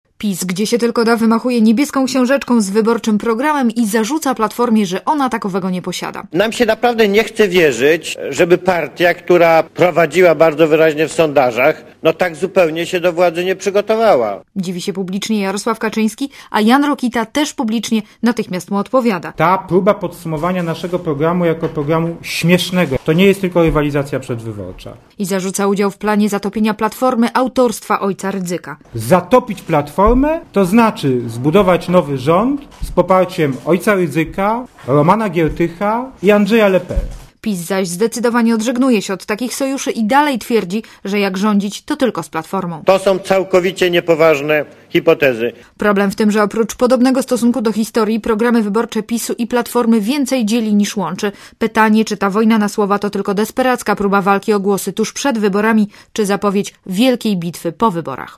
Źródło zdjęć: © RadioZet 21.09.2005 | aktual.: 22.09.2005 11:36 ZAPISZ UDOSTĘPNIJ SKOMENTUJ Relacja reportera Radia ZET